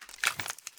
walther_draw.ogg